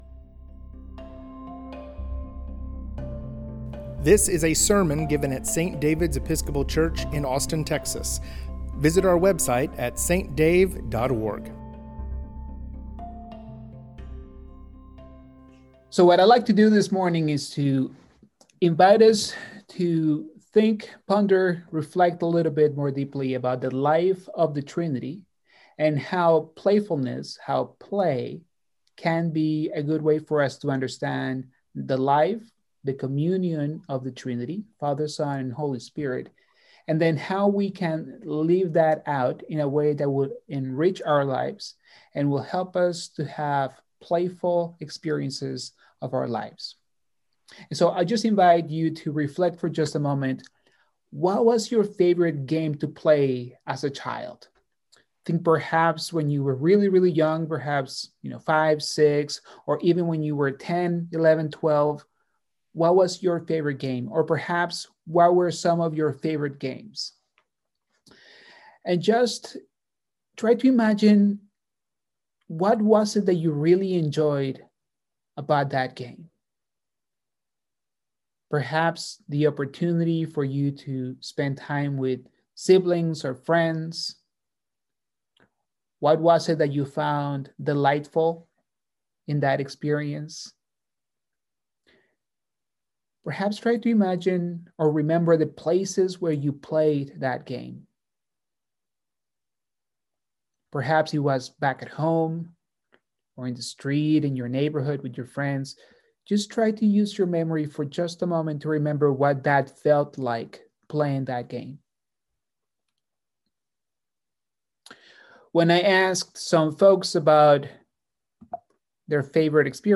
Summer Lecture Series: Playful Trinity